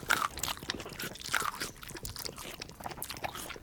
Divergent / mods / Soundscape Overhaul / gamedata / sounds / monsters / cat / eat_1.ogg
eat_1.ogg